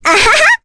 Requina-vox-Happy4.wav